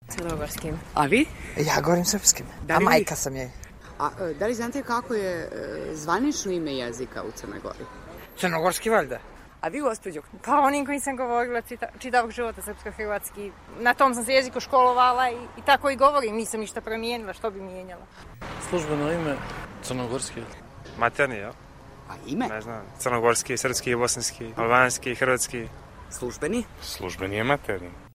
Ako je jezik bitna karakteristika identiteta onda smo građane pitali da li znaju kako se zove službeni jezik u Crnoj Gori.